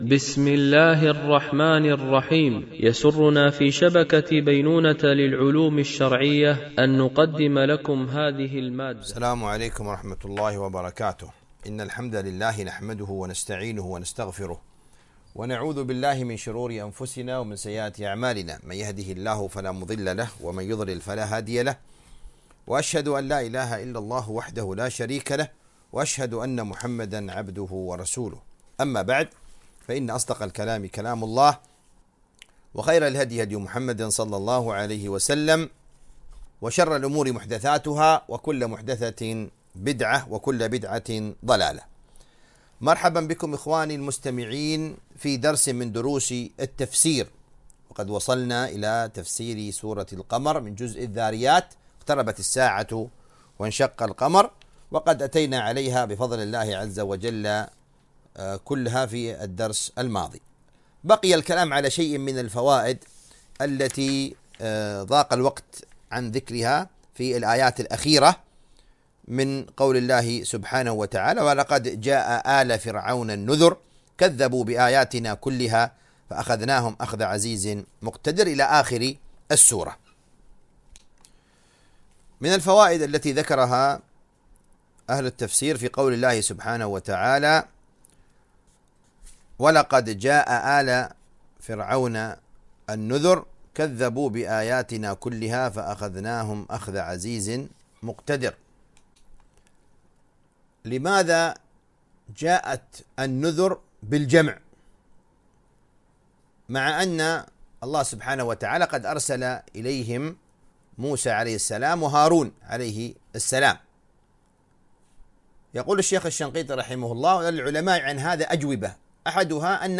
تفسير جزء الذاريات والأحقاف ـ الدرس 25 ( سورة الرحمن )